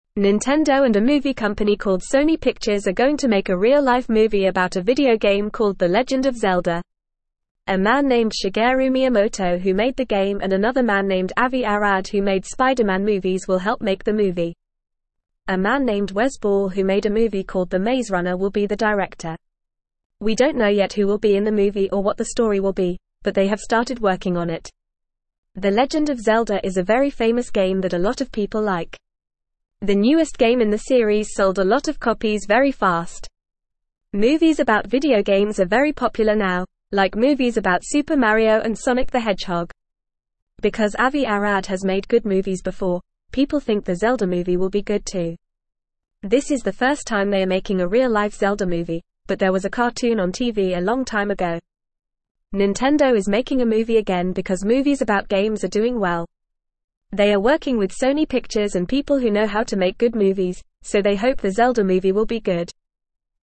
Fast
English-Newsroom-Lower-Intermediate-FAST-Reading-Nintendo-and-Sony-Making-The-Legend-of-Zelda-Movie.mp3